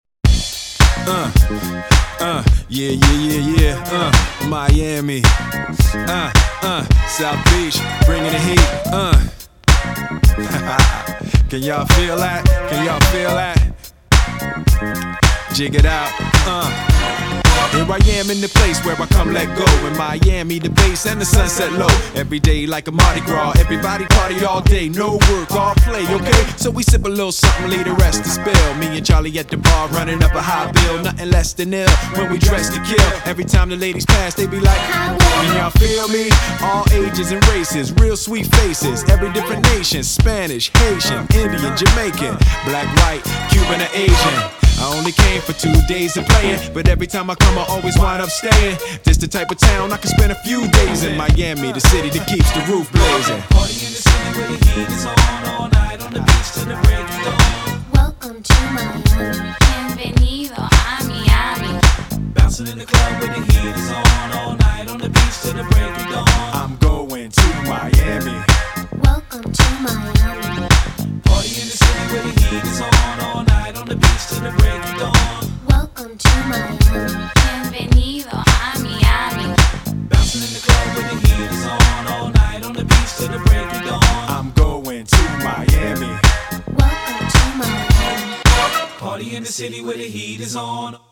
BPM107
Audio QualityPerfect (High Quality)